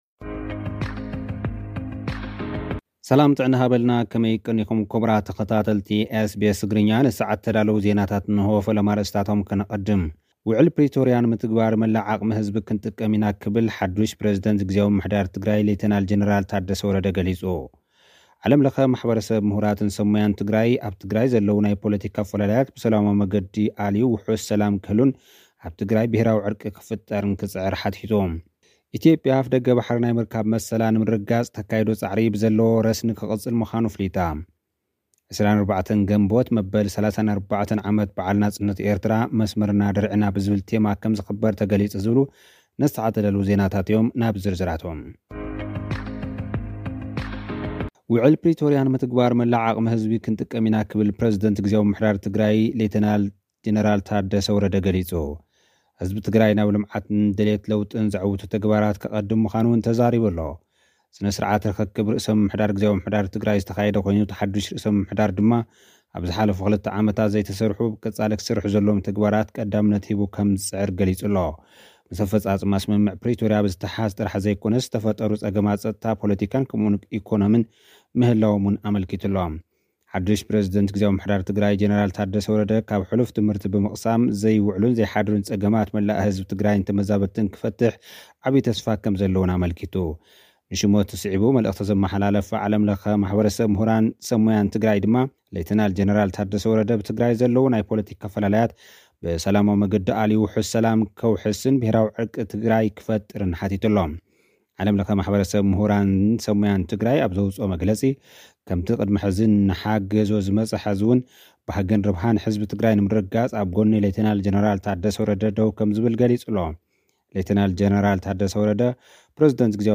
ኢትዮጵያ፡ ' ንሕቶ መሰል ኣፍደገ ባሕሪ ተካይዶ ጻዕሪ ብዘለዎ ረስኒ' ክቕጽል ምዃኑ ኣፍሊጣ።(ጸብጻብ)